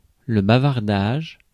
Ääntäminen
IPA: [ba.vaʁ.daʒ]